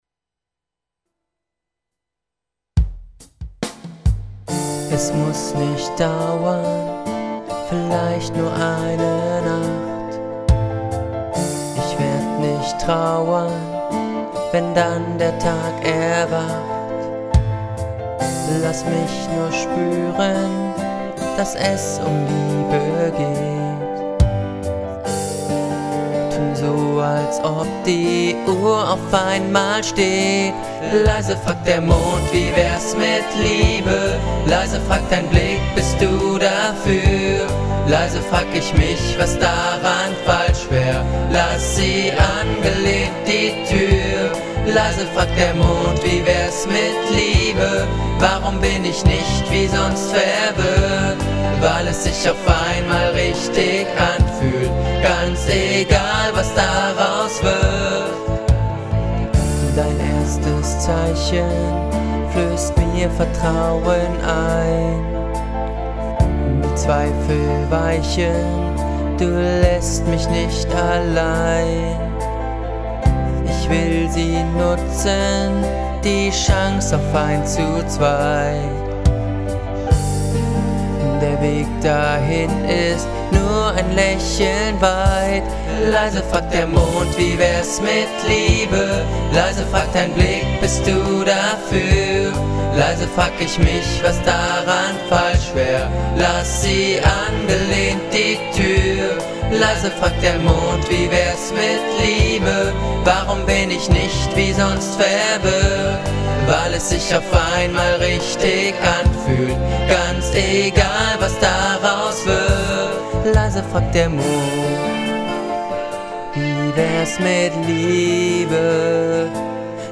Leise fragt der Mond [Schlager]
Diesmal habe ich die Mididateien in einzelnen Spuren rüber gezogen und versucht einen besseren Mix damit hinzubekommen und etwas sanfter zu singen.